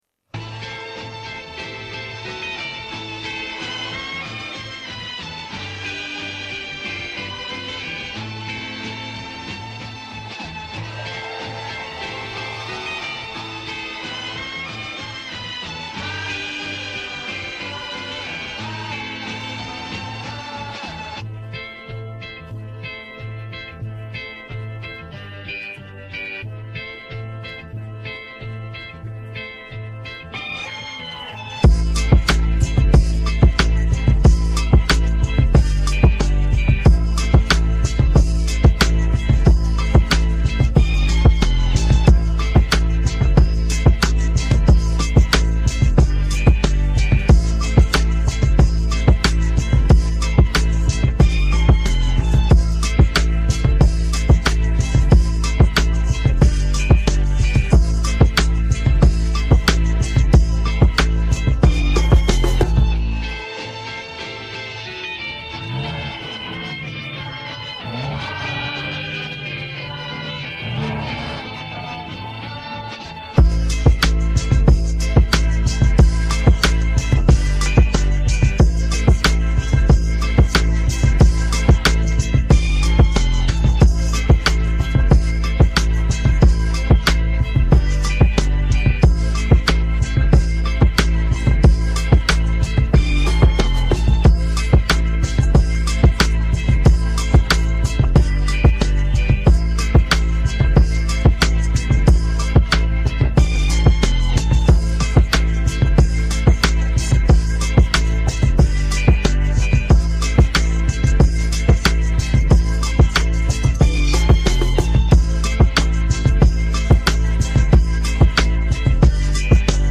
We have the instrumental of the new song right here.